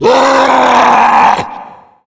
controller_die_0.ogg